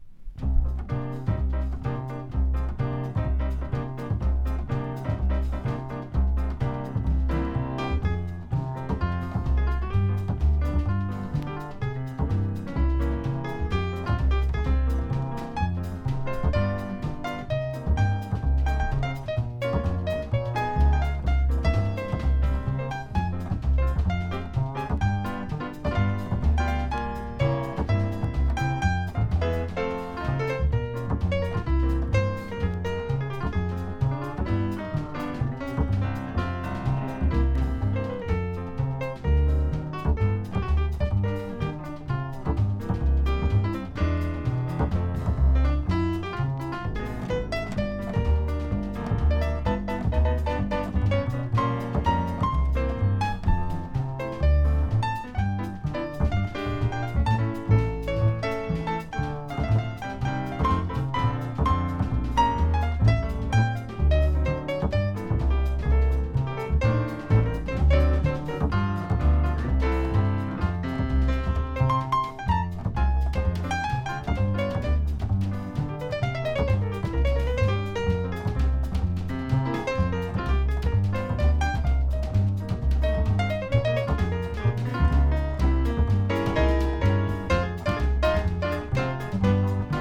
• JAZZ
# 和ジャズ